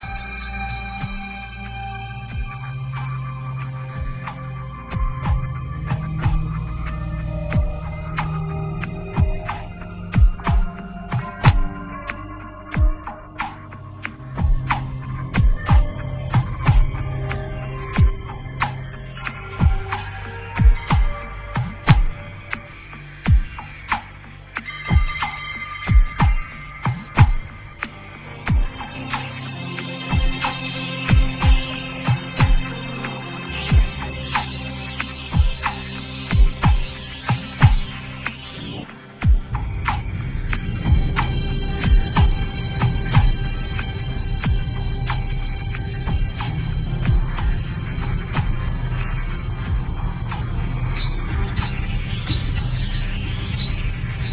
contains Electro,Ethno,Industrial,Dark,Experimental.. etc.
you will hear some cut&paste excerpts..